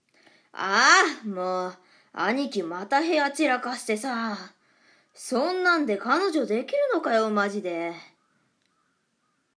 サンプルボイス ちょっとオカン 【少年】